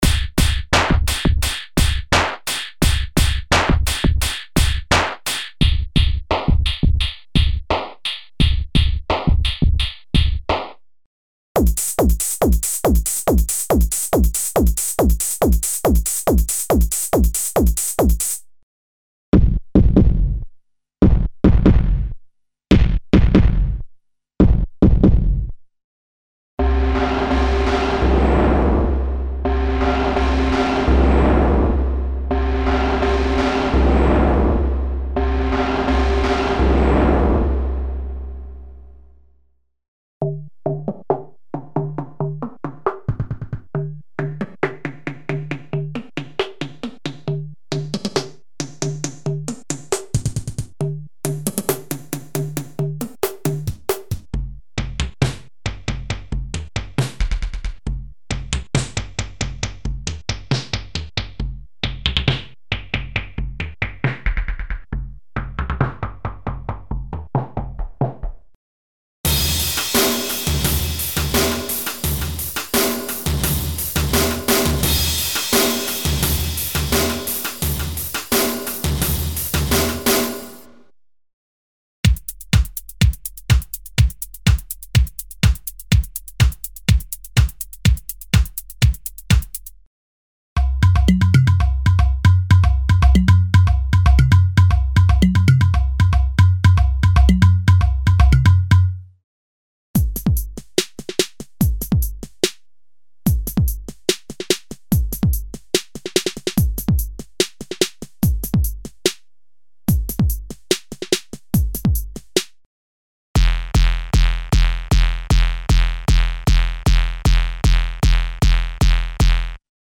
Acoustic, electronic and experimental drums & percussion - drum kits and single drum programs for a wide variety of music styles, ranging from Jazz, Rock and Ethno to Hip-Hop and underground electronic music. These programs were created to take advantage of the special controller settings for internal DSP modulations (e.g. filter, pitch, shaper, distortion, etc.).
Info: All original K:Works sound programs use internal Kurzweil K2600 ROM samples exclusively, there are no external samples used.
K-Works - Drums & Percussion - EX - Special Bundle (Kurzweil K2xxx).mp3